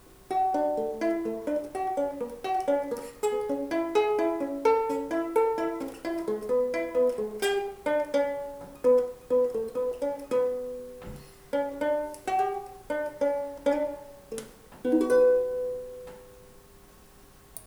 It's a tenor ukulele with a sweet tin body.